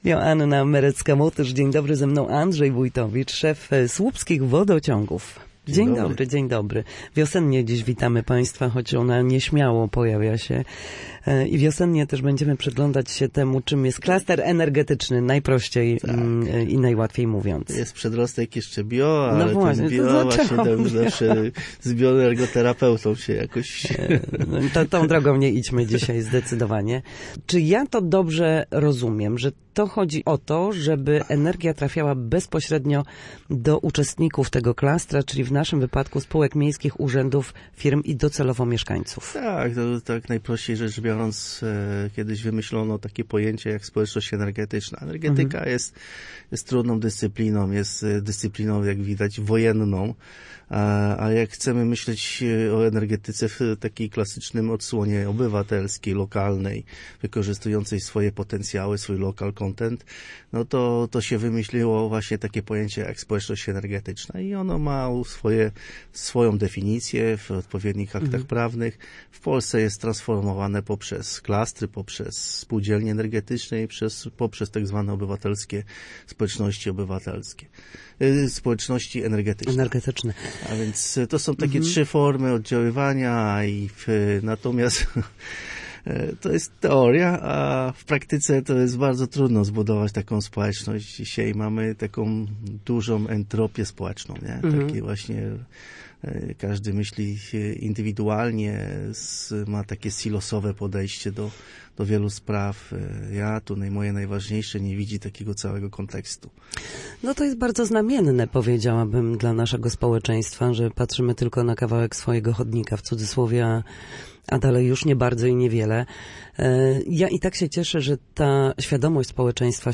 Na naszej antenie mówił o klastrze energetycznym – czym jest, kto wchodzi w jego skład oraz jakie korzyści przynosi ten pionierski projekt.